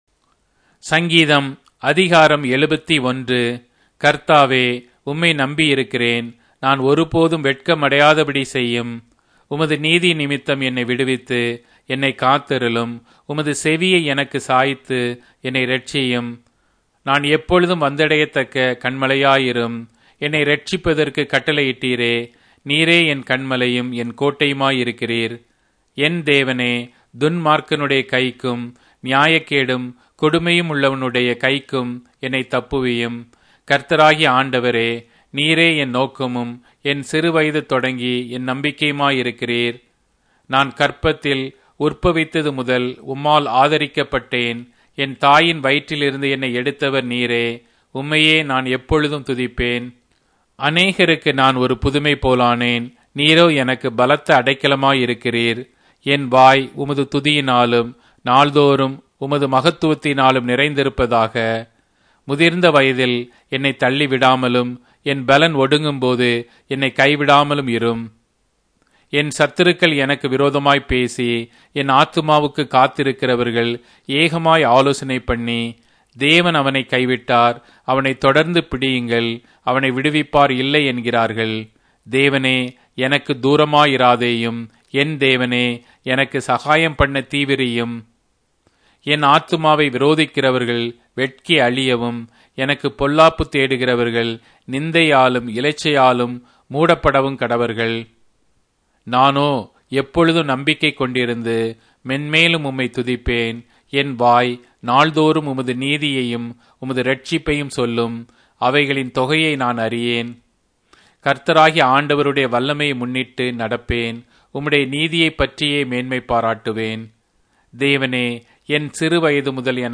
Tamil Audio Bible - Psalms 129 in Orv bible version